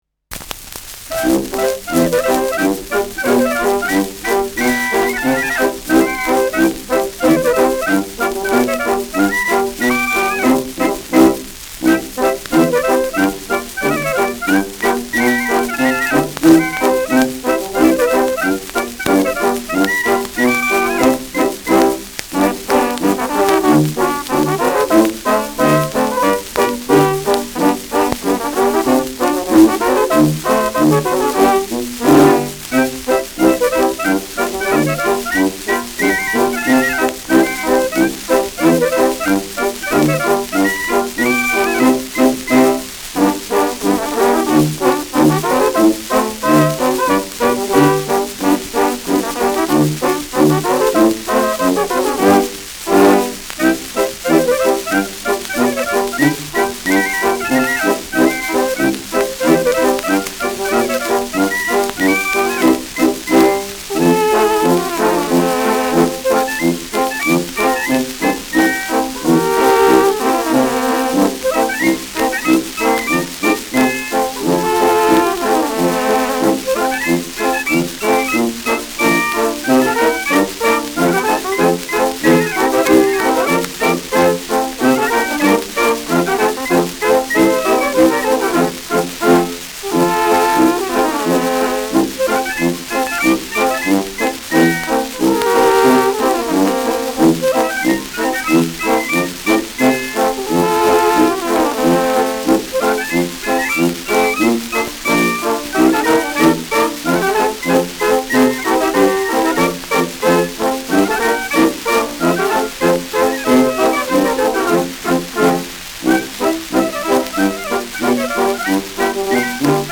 Schellackplatte
präsentes Rauschen : präsentes Knistern : leichtes Leiern : vereinzeltes Knacken : Tonnadel „rutscht“ bei 2’33’’ über einige Rillen
Mit Nachspruch.
[Nürnberg] (Aufnahmeort)